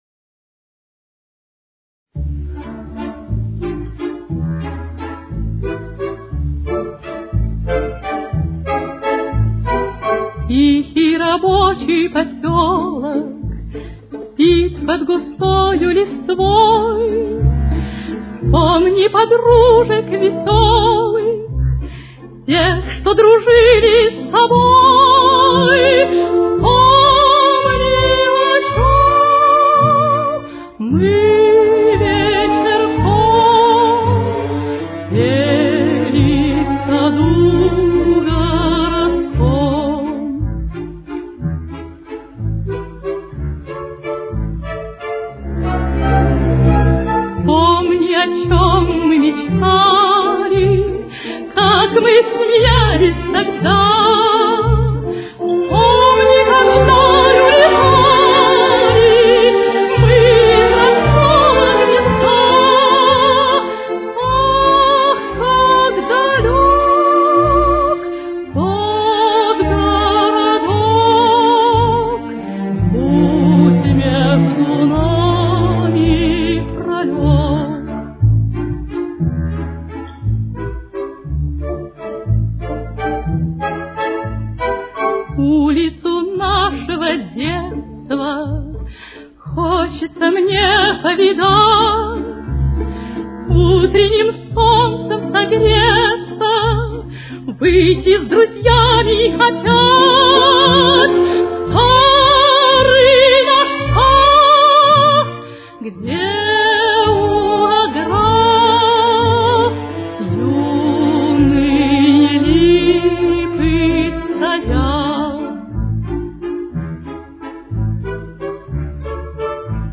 Ре минор. Темп: 180.